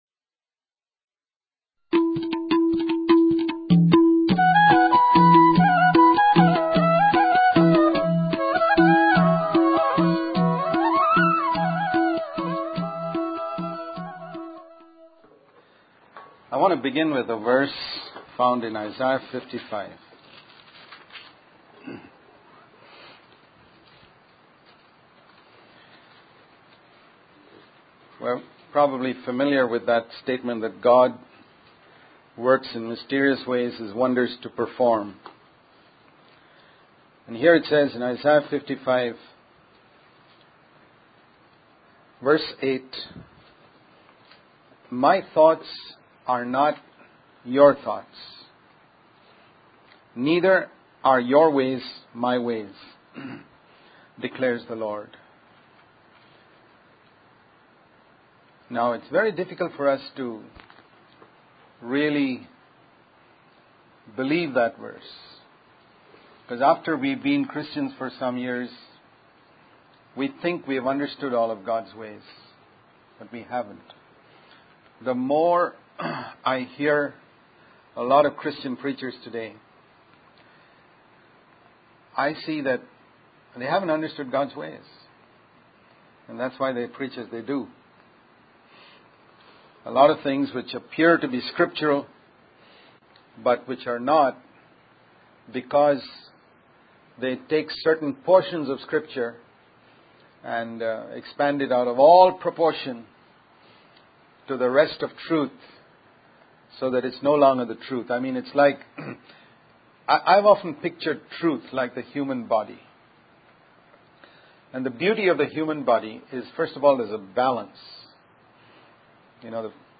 In this sermon, the speaker discusses four ways in which God's ways differ from our expectations. The first area is failure, where the speaker explains that failure is essential for our spiritual growth and humility.